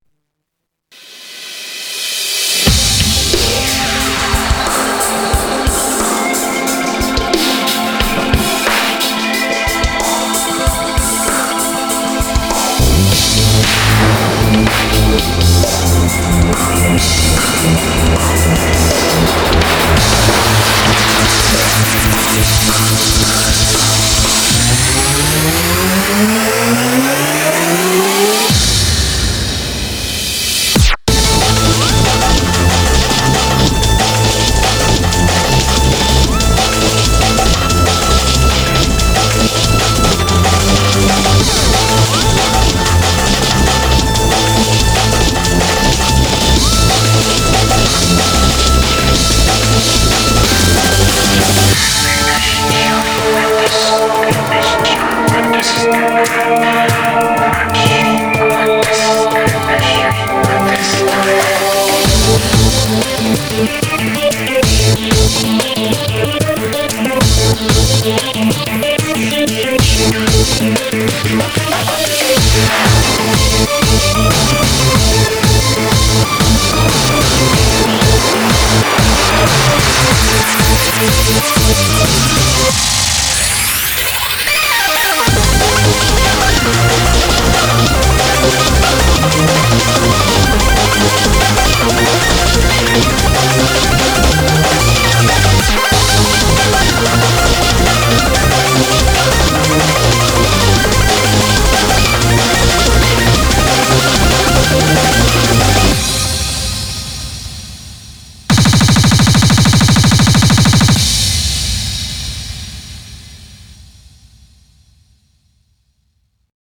BPM47-744